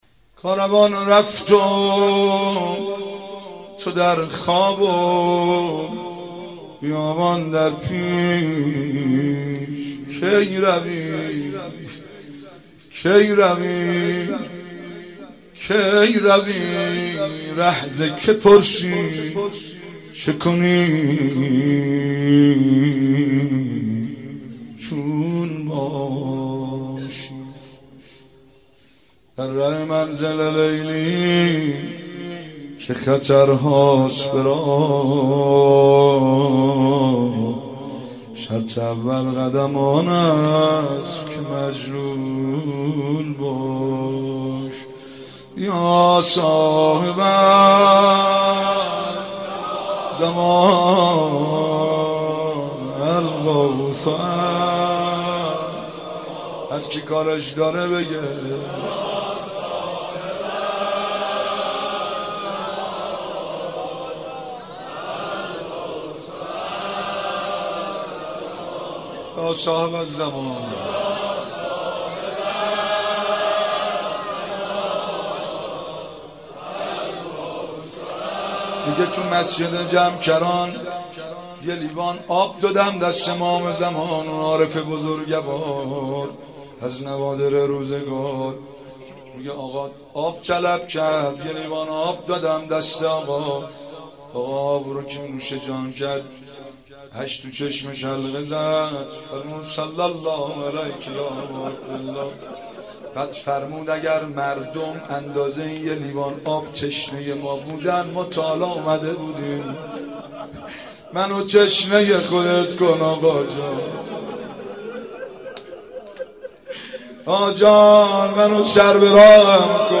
شب دوم فاطمیه 95 - مناجات